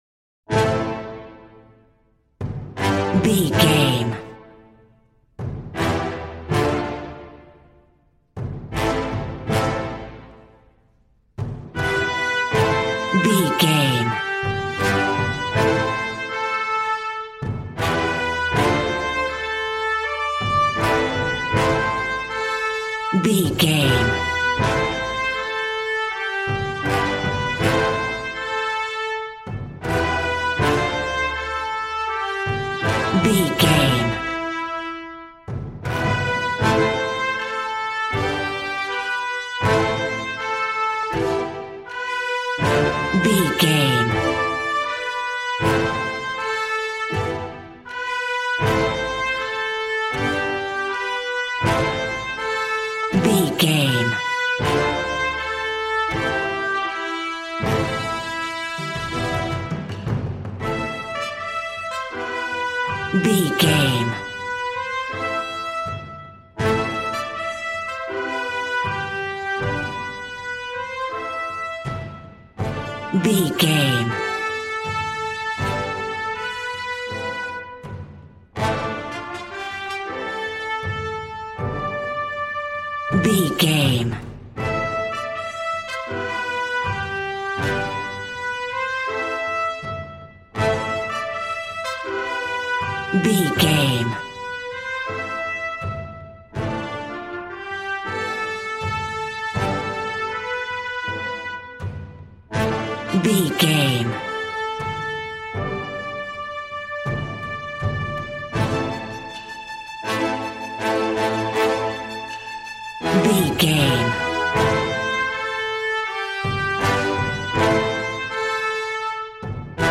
Aeolian/Minor
brass
strings
violin
regal